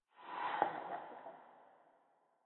Minecraft Version Minecraft Version snapshot Latest Release | Latest Snapshot snapshot / assets / minecraft / sounds / ambient / underwater / additions / animal2.ogg Compare With Compare With Latest Release | Latest Snapshot